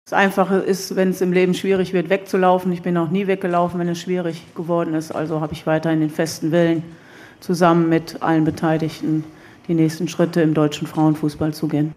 Das sagte die 55-Jährige auf der Abschluss-Pressekonferenz in Australien.